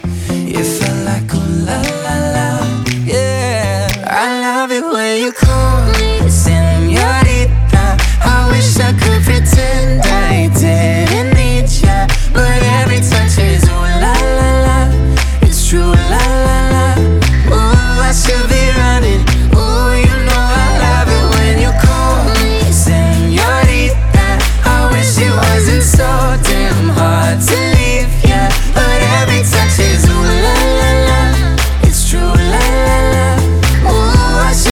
• Качество: 320, Stereo
поп
гитара
дуэт
медленные